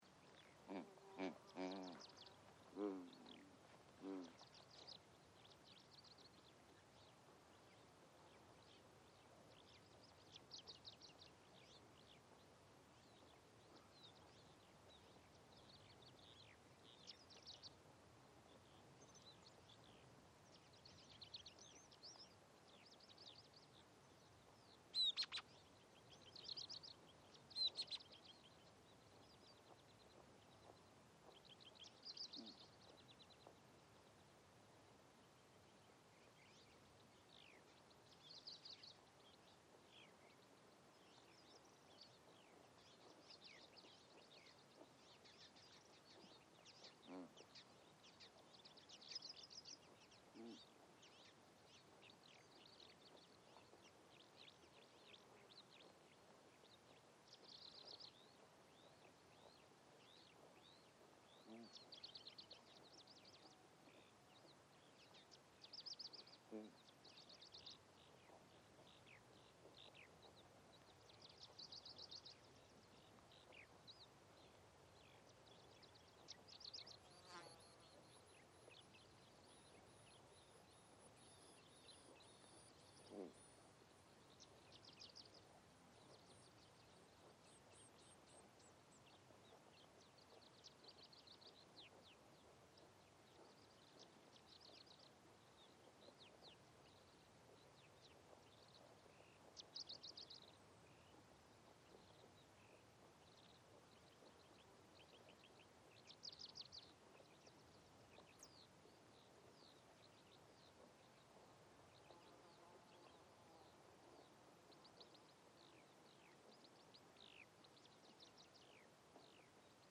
Звуки тибетской природы в 2020 году с яками